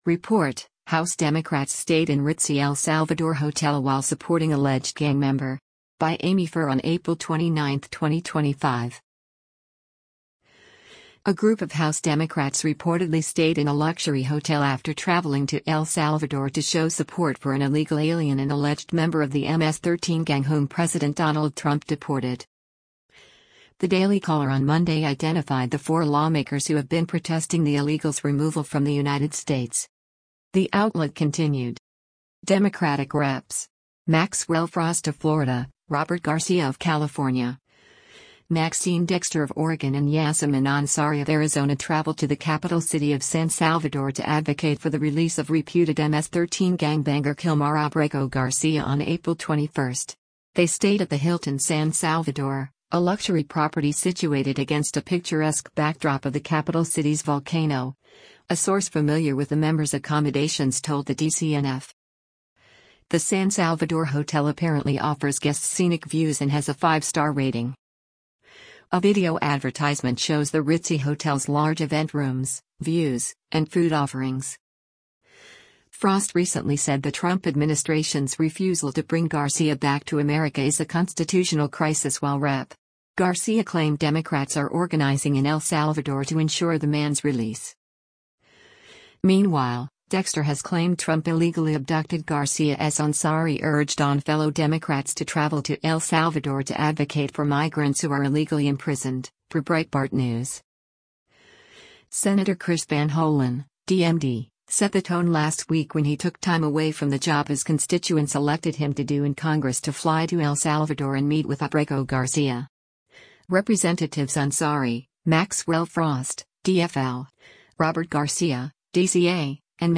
Democratic US Congressman Maxwell Frost speaks during a press conference on the liberation